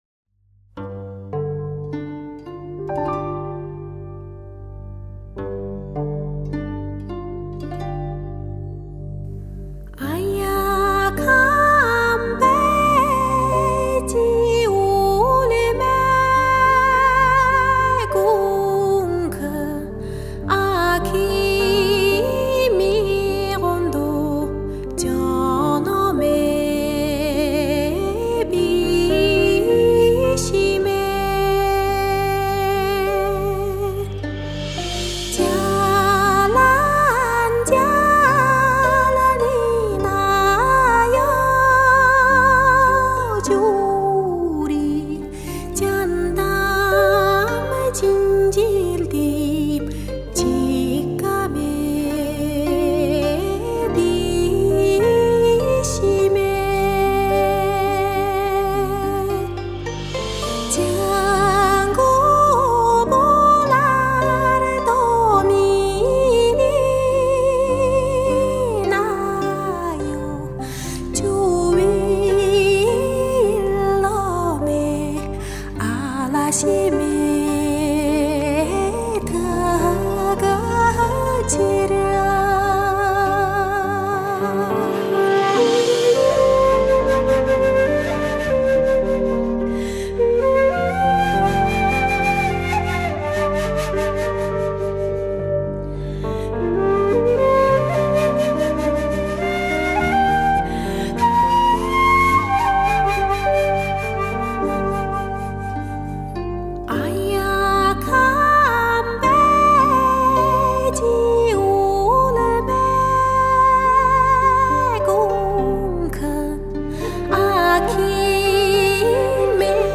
本专辑里我们首次收入了鄂温克民族索伦、通古斯、雅库特三大部落不同风格的民歌精品，并邀请到各部落的歌手用本地区方言来演唱。